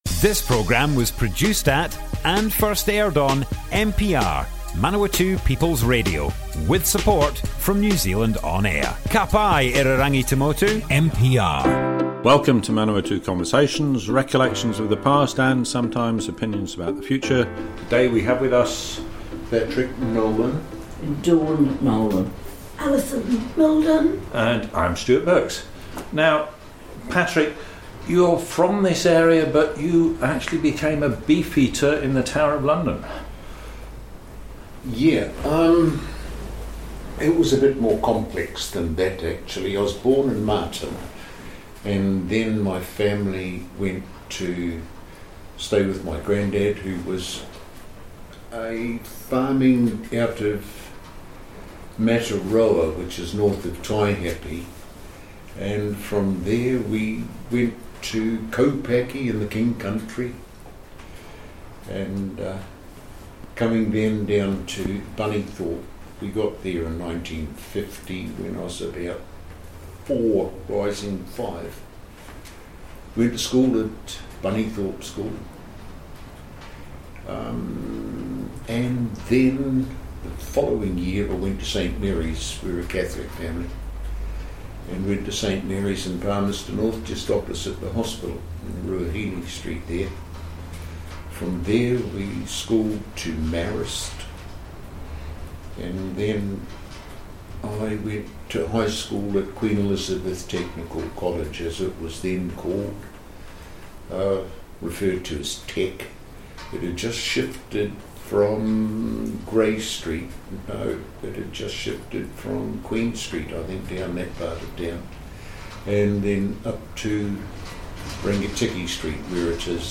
Manawatu Conversations More Info → Description Broadcast on Manawatu People's Radio, 5th July 2022, Part 1 of 5.
oral history